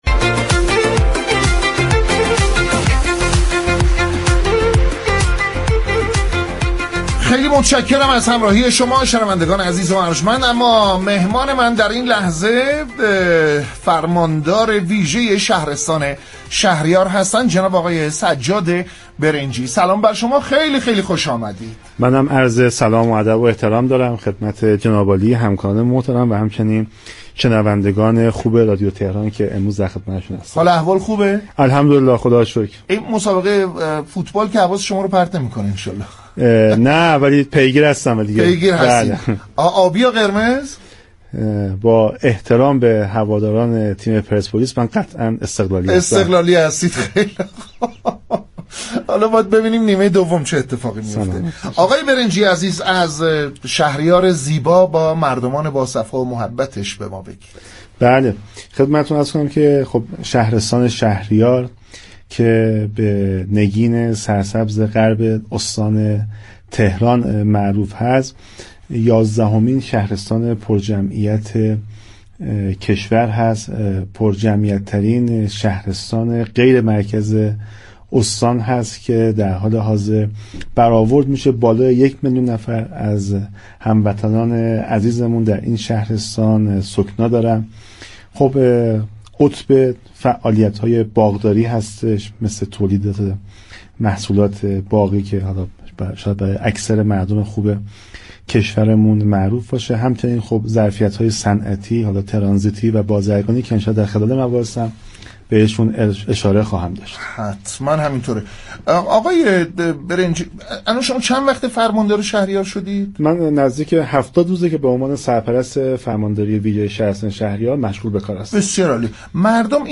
به گزارش پایگاه اطلاع رسانی رادیو تهران، سجاد برنجی فرماندار ویژه شهرستان شهریار با حضور در استودیو پخش زنده رادیو تهران با برنامه «اینجا تهران است» گفت و گو كرد.